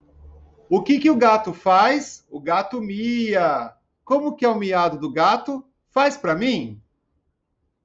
MIAUUUUUUU